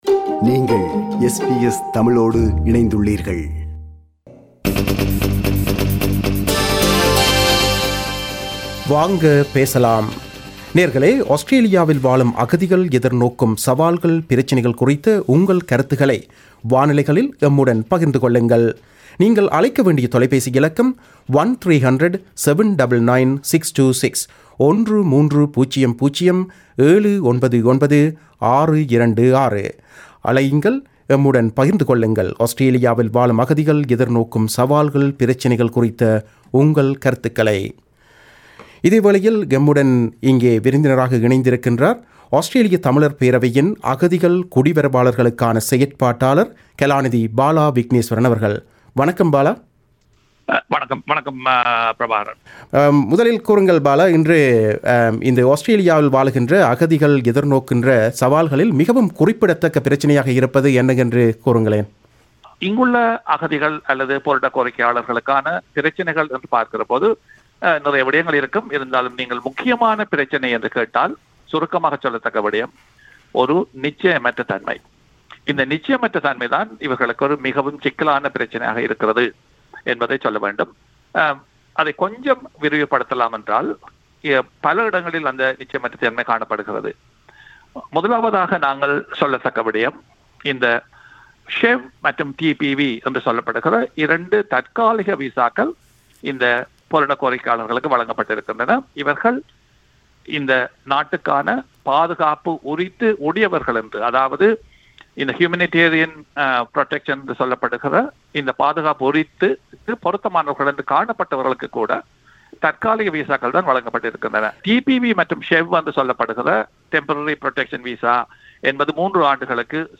Talkback - What are the issues that refugees in Australia face?